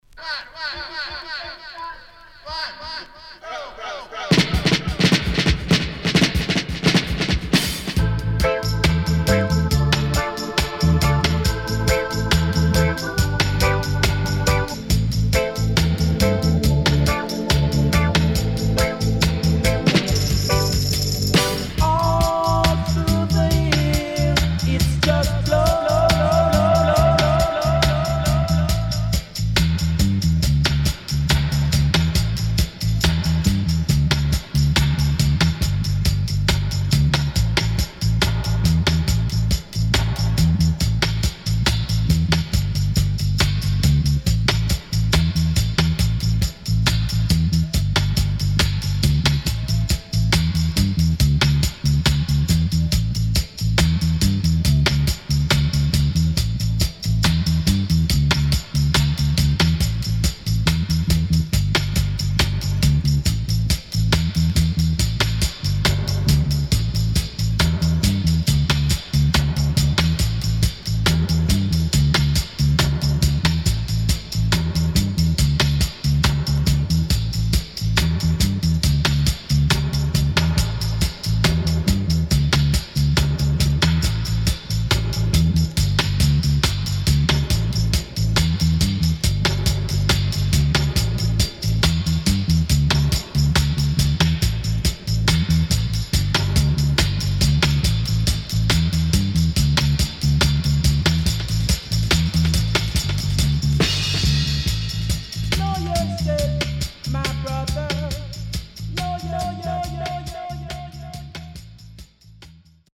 SIDE A:少しチリノイズ、プチノイズ入りますが良好です。